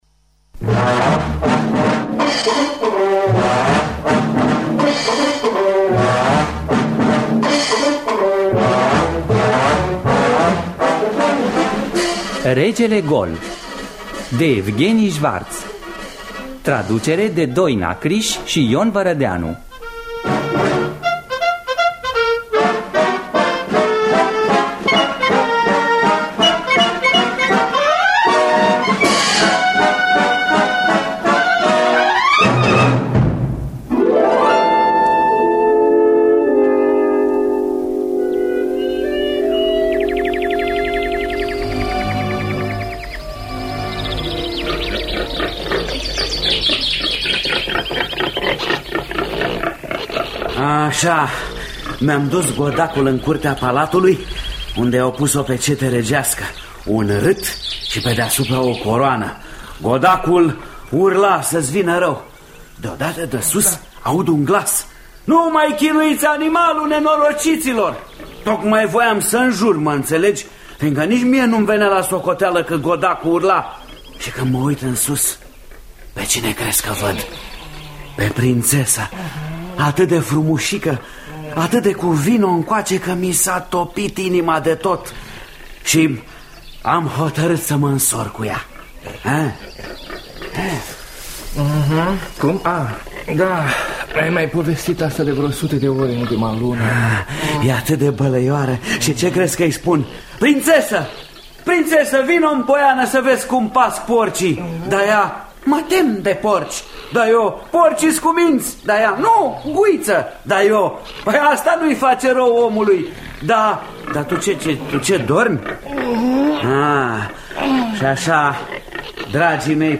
Regele gol de Evgheni Şvarţ – Teatru Radiofonic Online